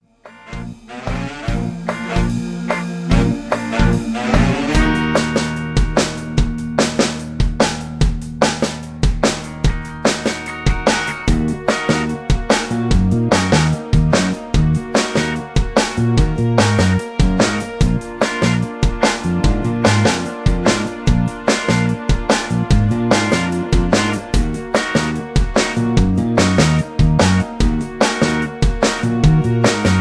Backing Track
Backing Vocals